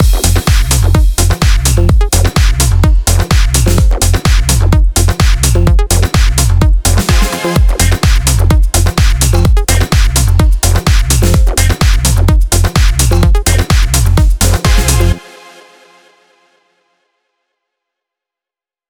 VDE 127BPM Rebound Mix.wav